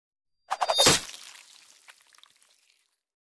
Звуки удара ножом
Нож вонзили в тело человека